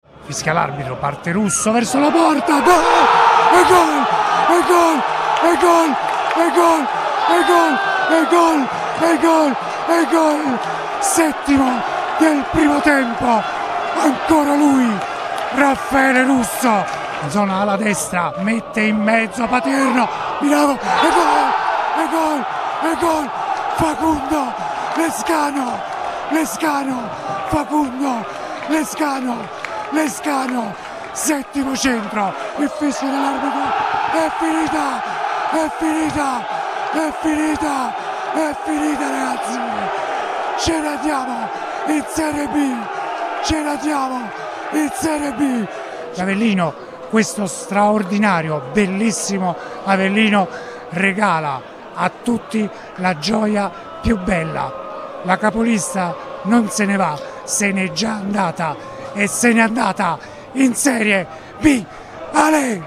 RIASCOLTA L’ESULTANZA